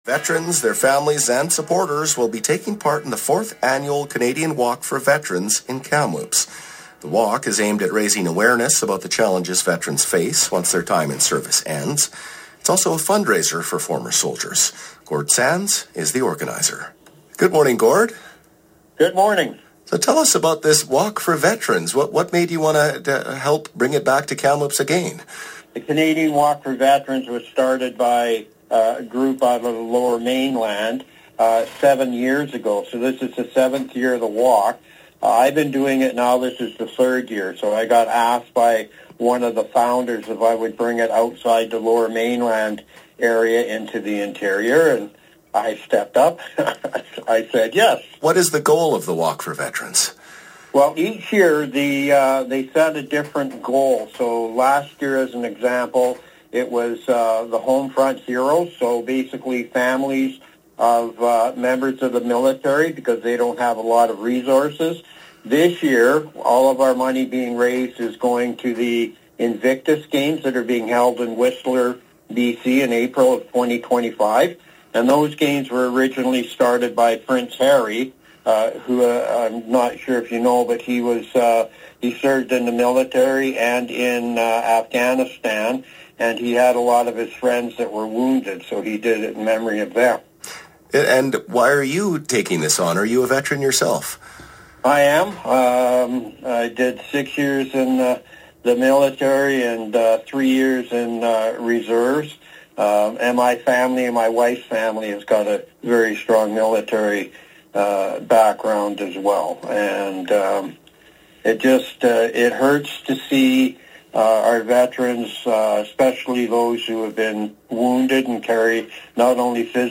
CBC Radio: Interview